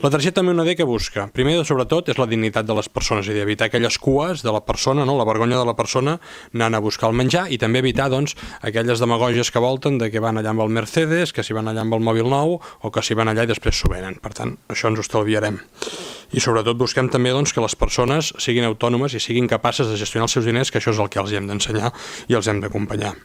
Durant l’últim ple municipal, l’alcalde Marc Buch va explicar que aquest nou model busca preservar la dignitat de les persones usuàries i millorar el control sobre la gestió dels ajuts: